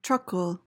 PRONUNCIATION: (TRUHK-uhl) MEANING: verb intr.:To act in a servile manner. verb tr.:To move or roll on small wheels. noun:1.